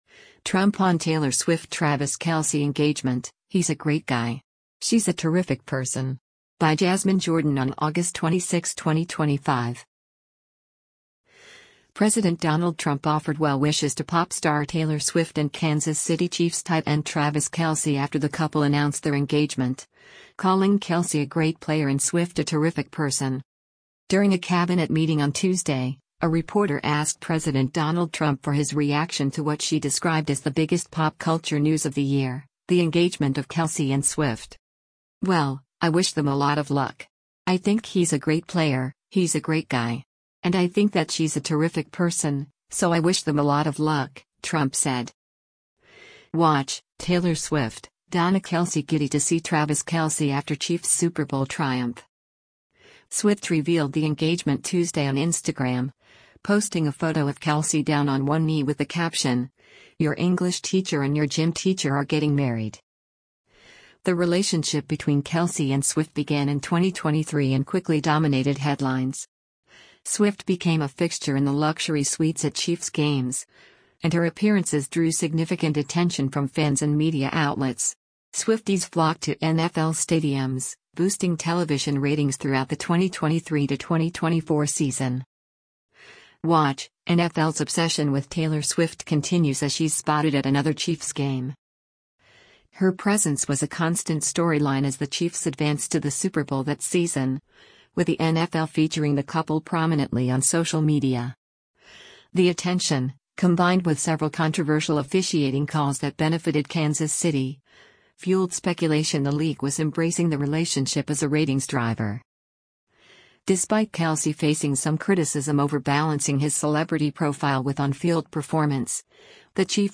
During a cabinet meeting on Tuesday, a reporter asked President Donald Trump for his reaction to what she described as the “biggest pop culture news of the year” — the engagement of Kelce and Swift.